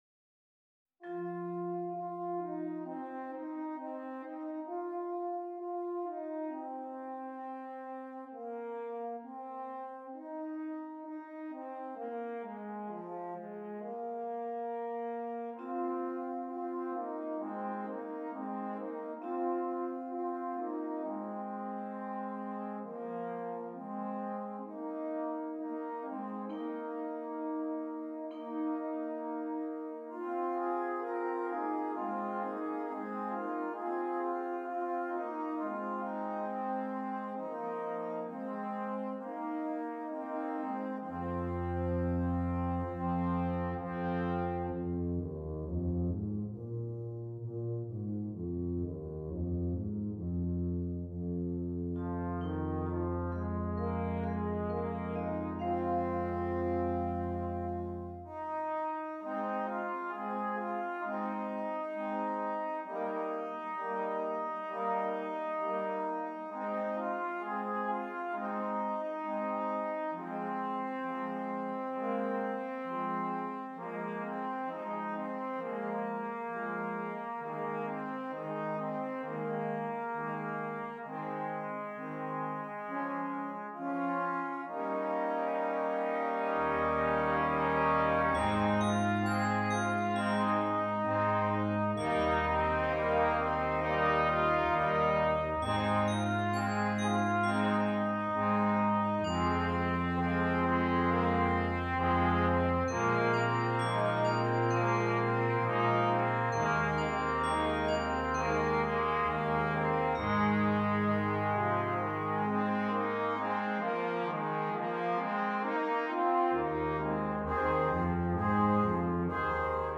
Brass Quintet (optional Percussion)